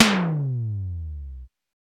TOM XC.TOM08.wav